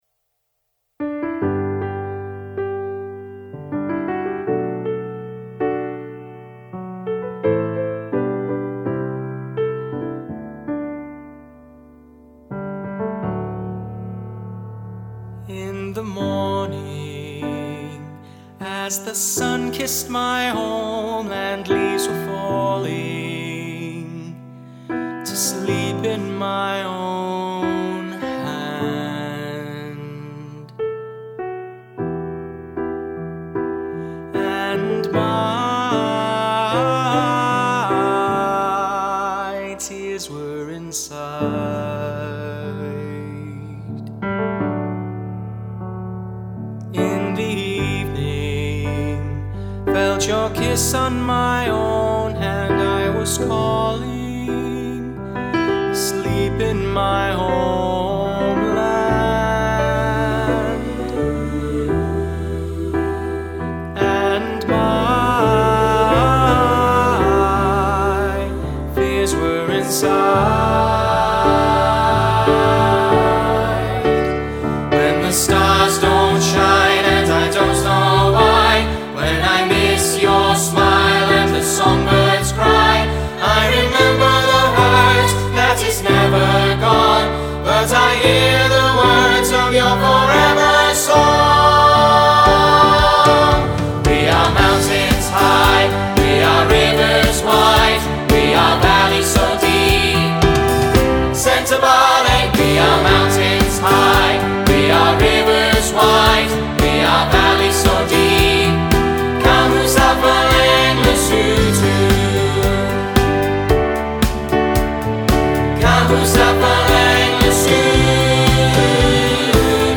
The song, ‘Forever Song’ was sung by over 1000 children to commemorate the nations of the Commonwealth at the event held at Durham Cathedral, organised by the Durham Lieutenancy and Mrs Sue Snowdon LL.